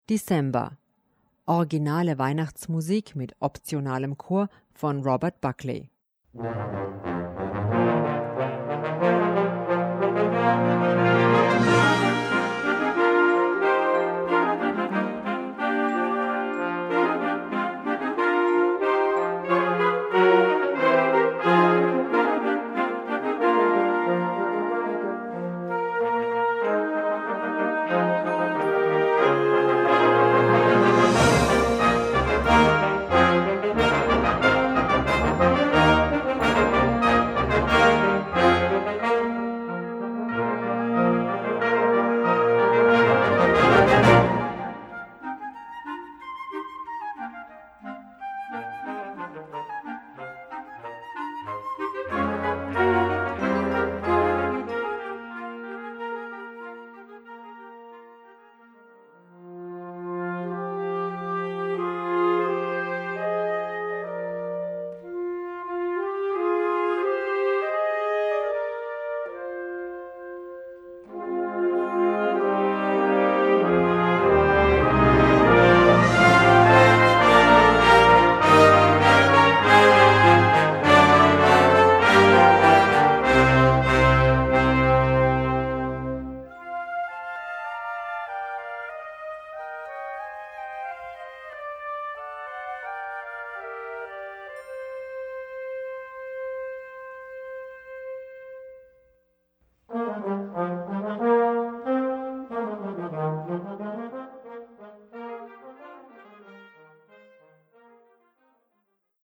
Besetzung: Blasorchester
schnell-langsam-schnellen Aufbau